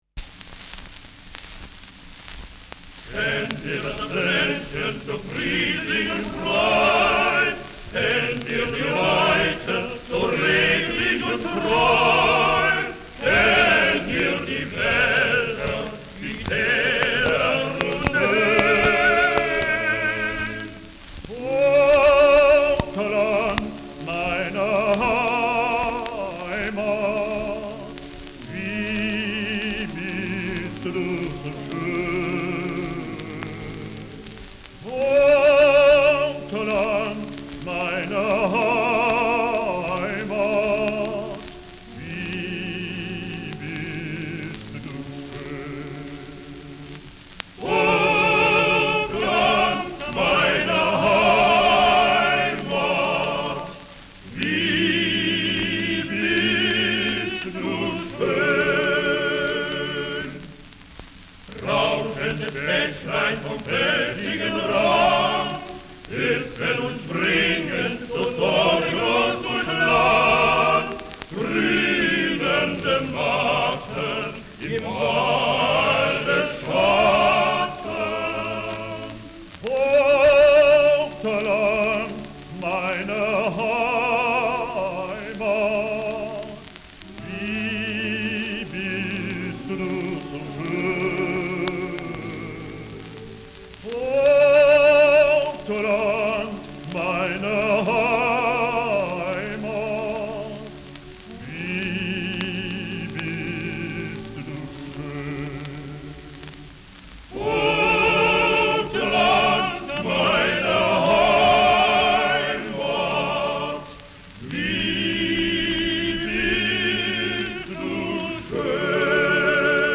Schellackplatte
leichtes Rauschen : leichtes Knistern : vereinzeltes Knacken
[Berlin] (Aufnahmeort)